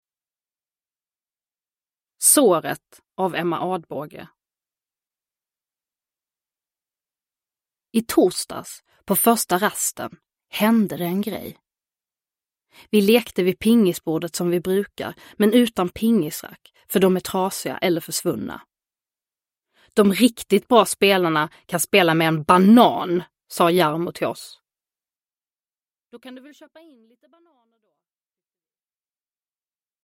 Såret – Ljudbok – Laddas ner
Uppläsare: Nour El Refai